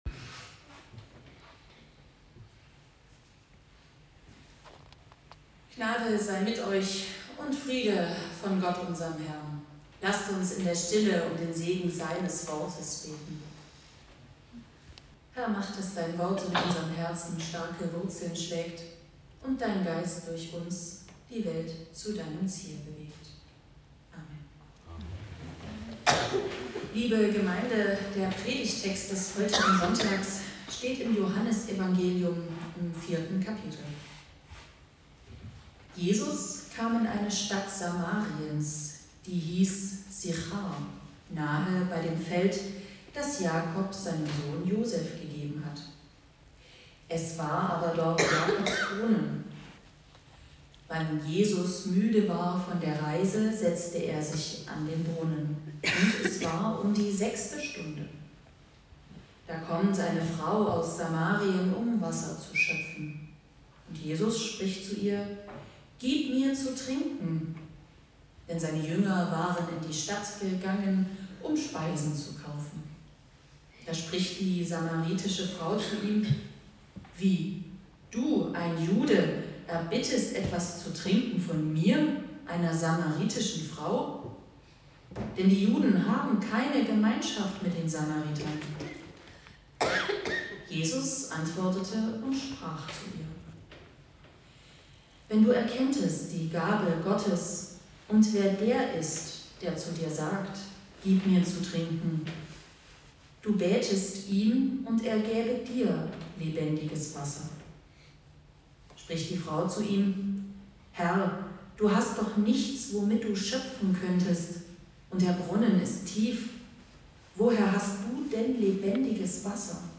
Passage: Johannes 4; 5-14 Gottesdienstart: Predigtgottesdienst Wildenau « Auf JESUS allein kommt es an!